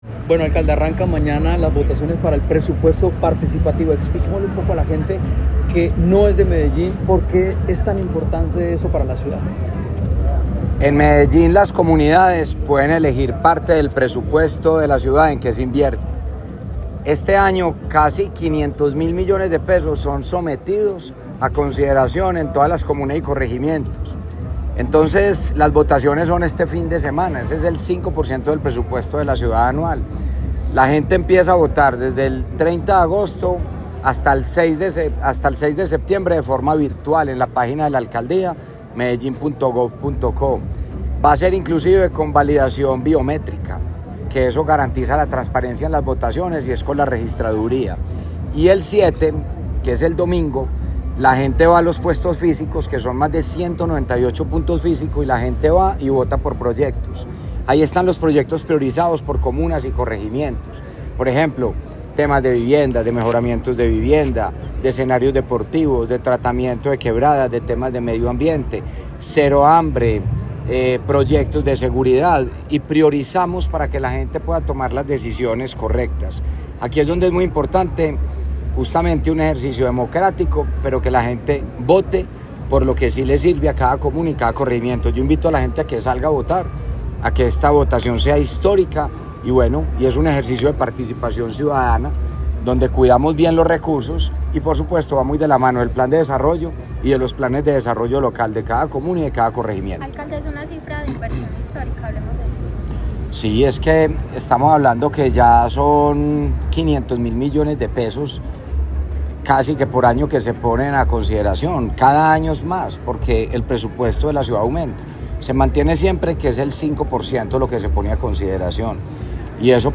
Declaraciones-del-alcalde-de-Medellin-Federico-Gutierrez.mp3